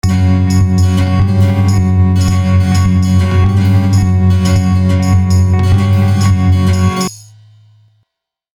MIDI Guitar Strumming Patterns